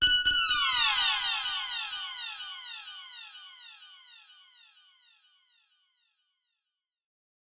FX (OZ-Alarm).wav